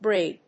ブライ